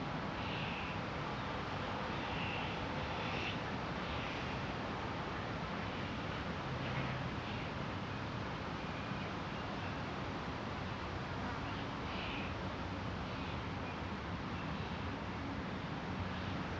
background_quiet_urban_park_loop_02.wav